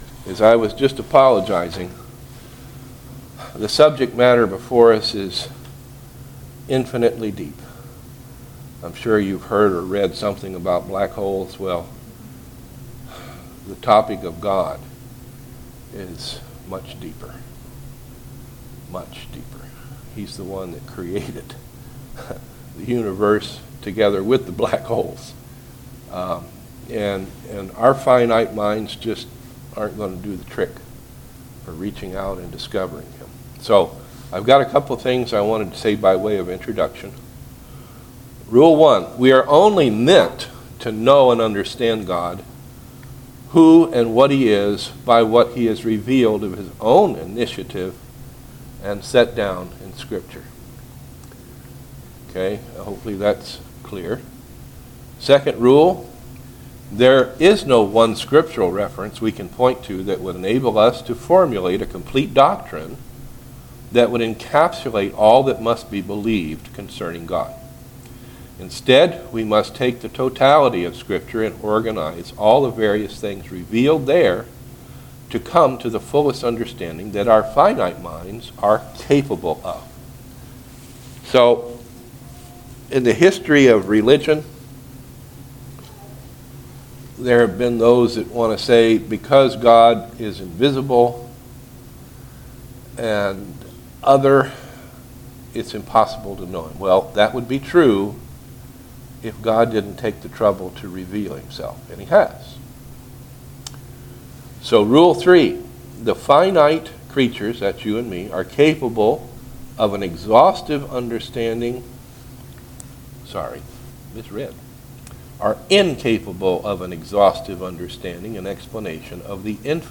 Service Type: Sunday School Handout